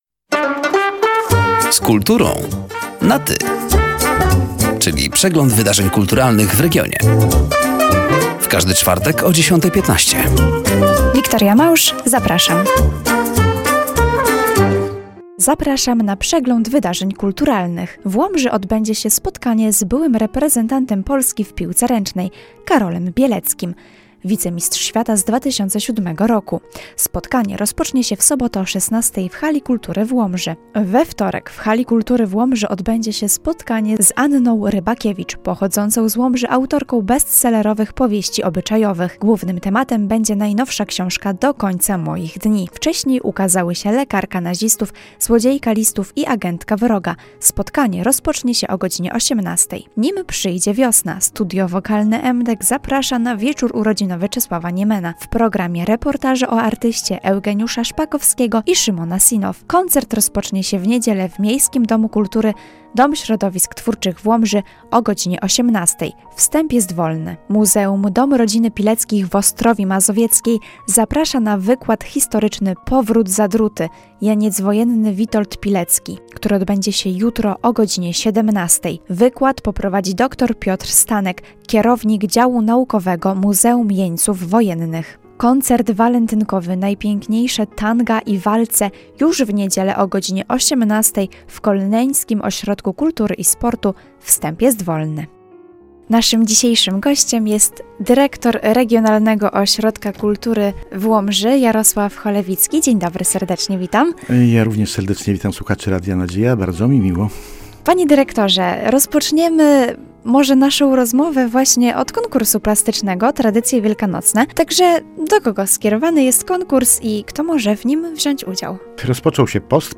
Zapraszamy do zapoznania się ze zbliżającymi się wydarzeniami oraz do wysłuchania rozmowy: